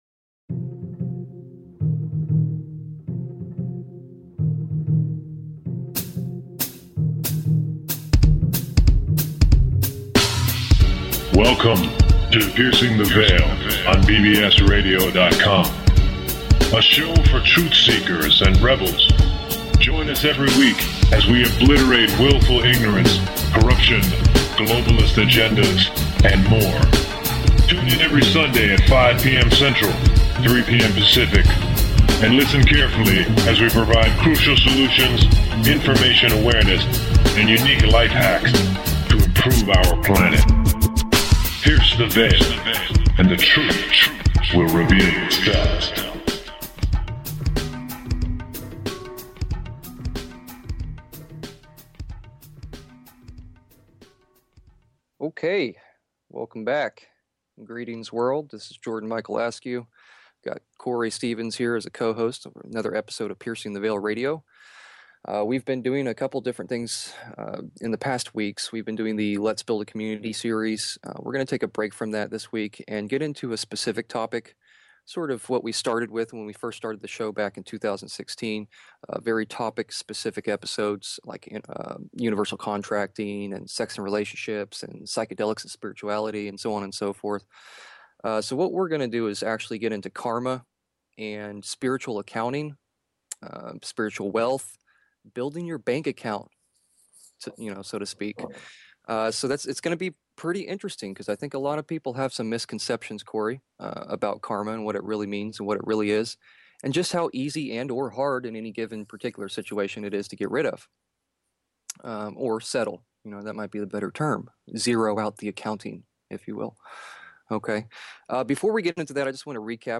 w Guest Caller Questions
A caller and future guest makes a special appearance.